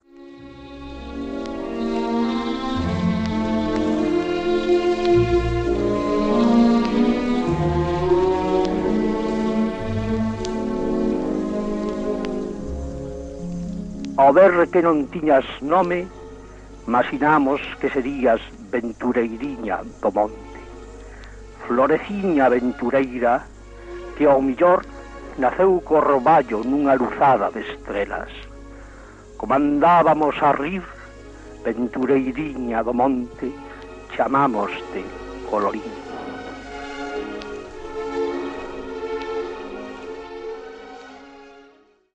1968. Poema do livro Roseira do teu mencer recitado por XMAB no programa "Poetas de Galicia" de Radio Nacional de España, reproduzido no programa "Diálogos entre ontem e hoje" de RNE, 1 Setembro 2007, que incluíu uma entrevista a Alfonso Álvarez Cáccamo sobre a figura de Álvarez Blázquez.